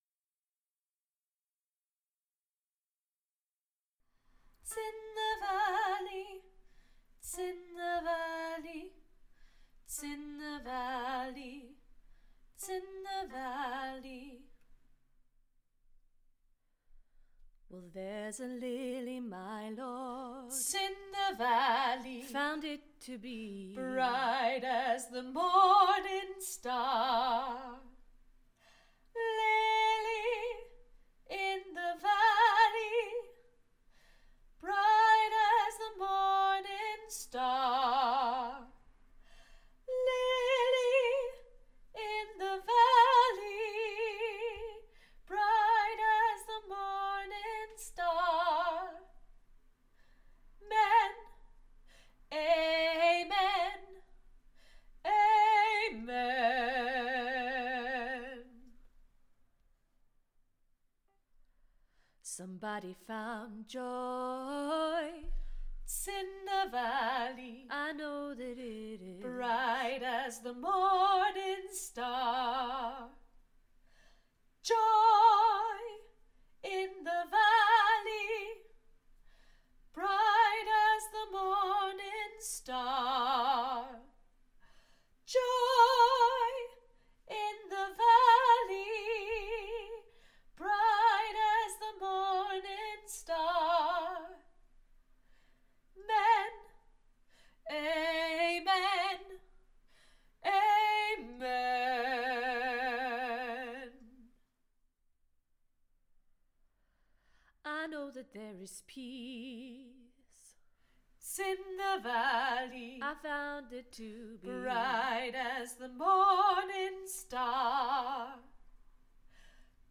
Lily In The Valley Bass